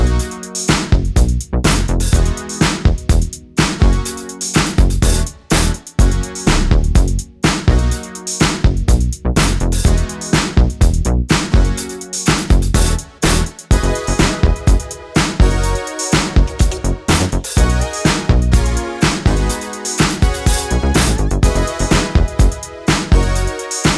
For Solo Female Soul / Motown 4:12 Buy £1.50